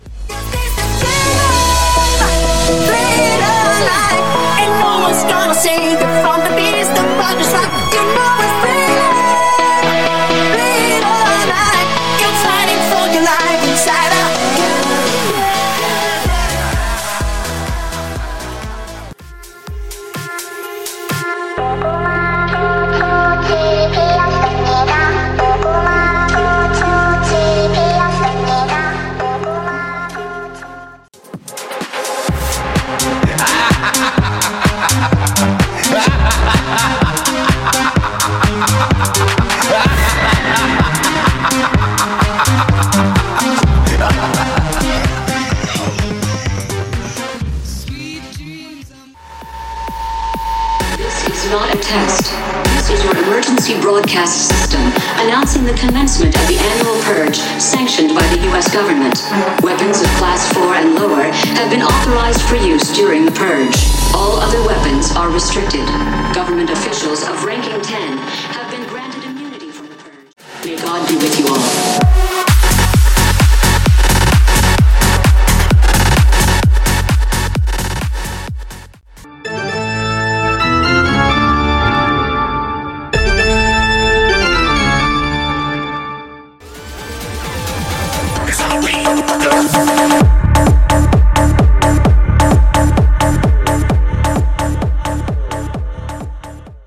Genre: 80's
Clean BPM: 120 Time